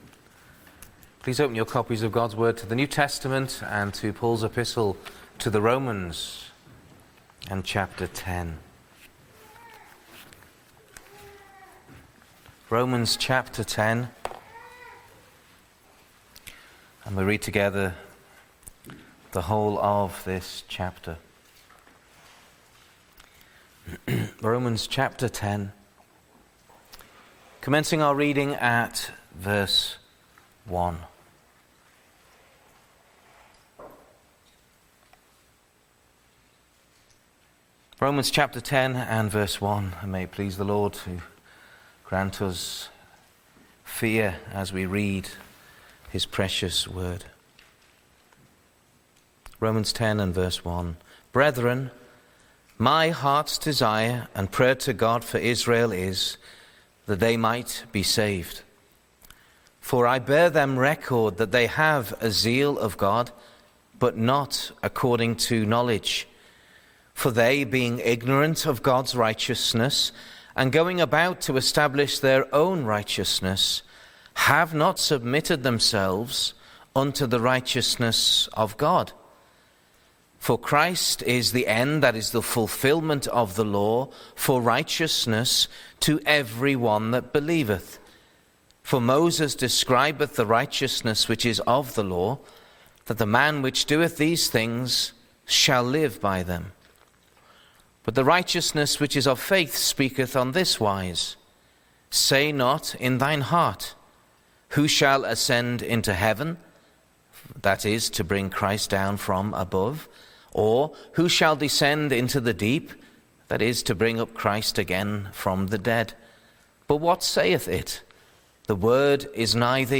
The Word of Faith | SermonAudio Broadcaster is Live View the Live Stream Share this sermon Disabled by adblocker Copy URL Copied!